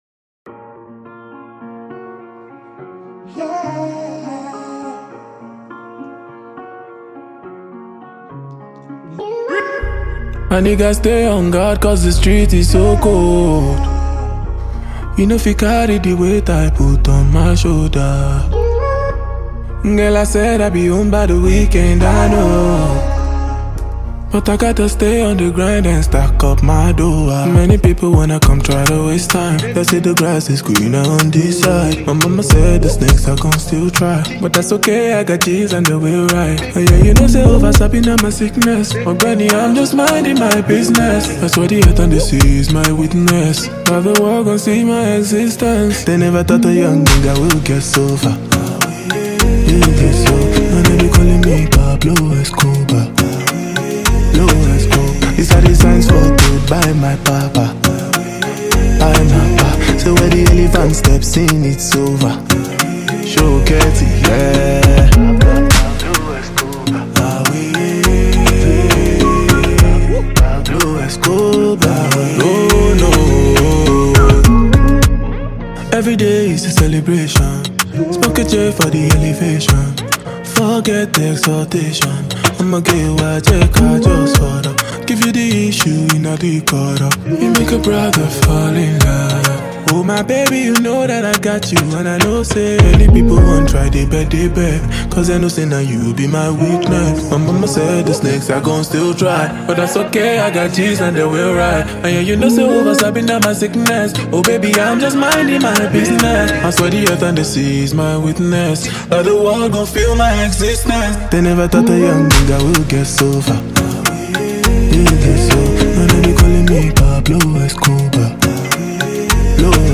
catchy Afrobeat record